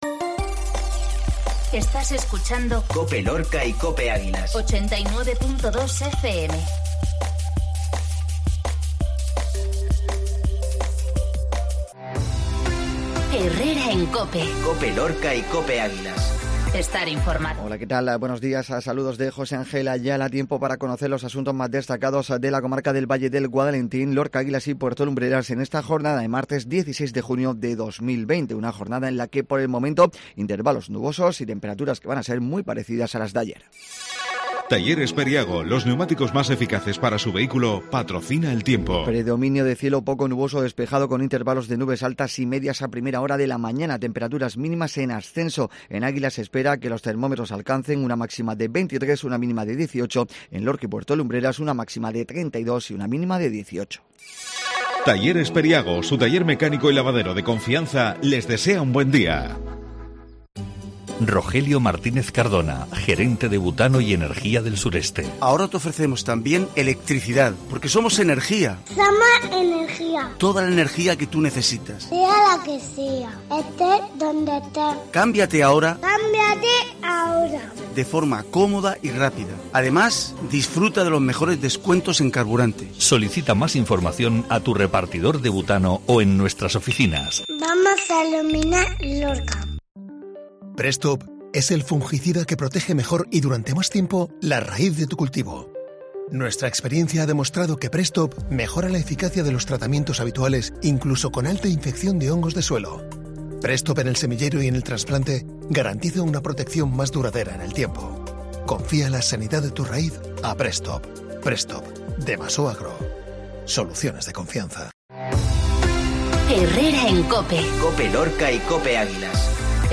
INFORMATIVO MATINAL MARTES 1606